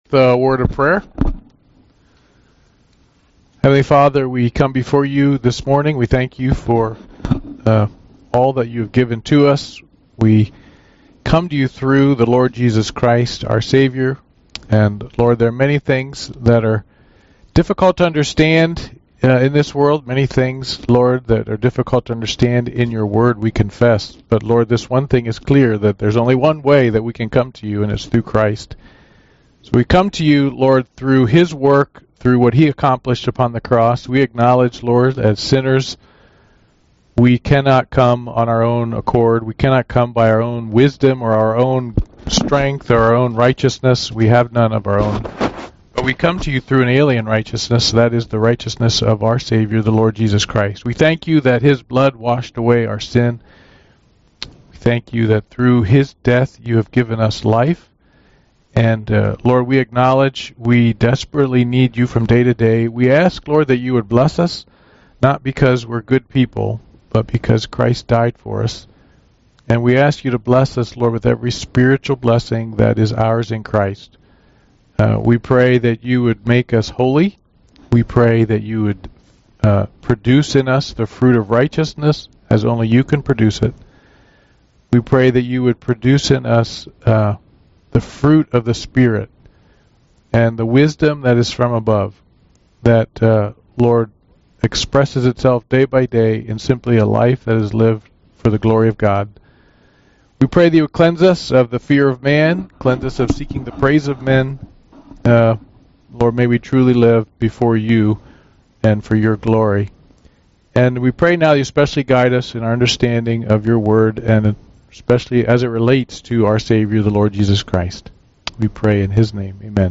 UN Service Type: Men's Bible Study « Sorrento